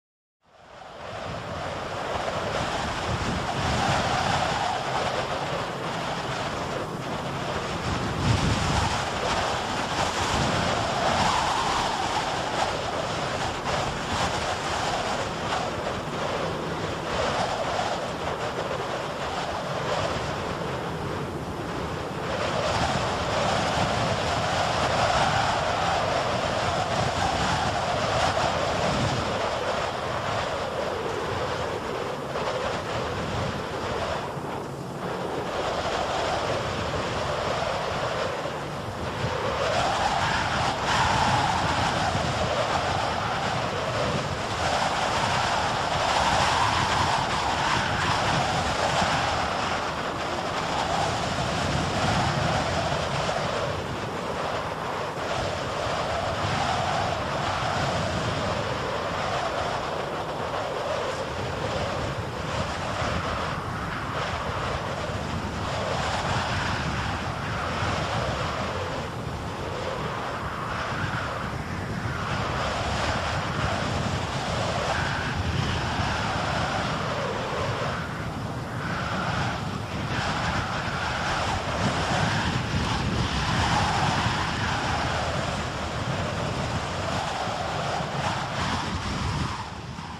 دانلود آهنگ باد 5 از افکت صوتی طبیعت و محیط
دانلود صدای باد 5 از ساعد نیوز با لینک مستقیم و کیفیت بالا
جلوه های صوتی